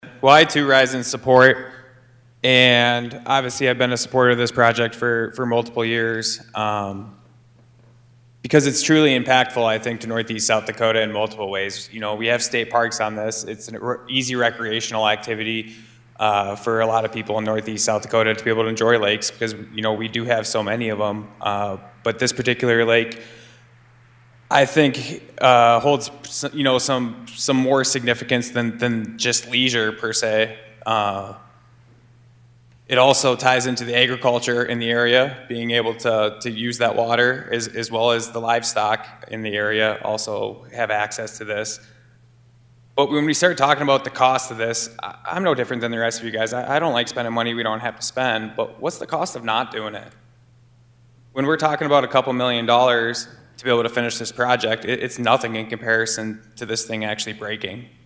SD Senate: